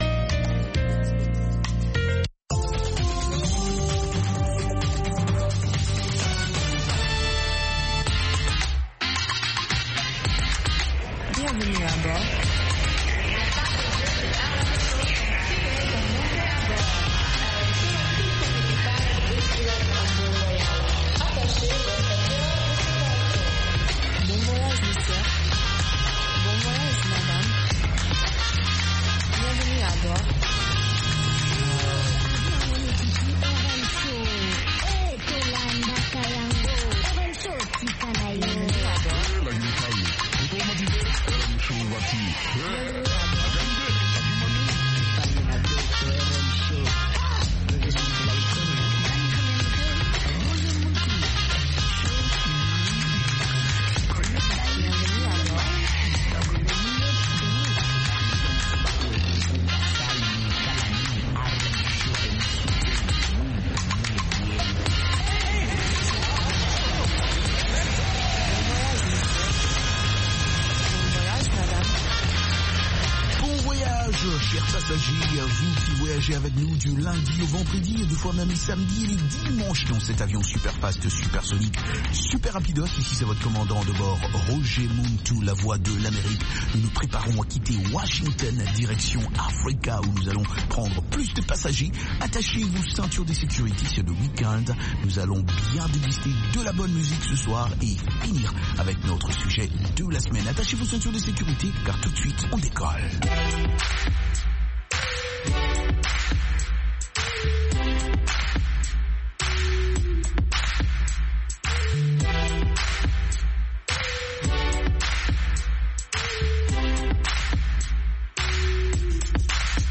Zouk, Reggae, Latino, Soca, Compas et Afro
interviews de divers artistes